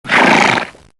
Heroes3_-_Unicorn_-_DefendSound.ogg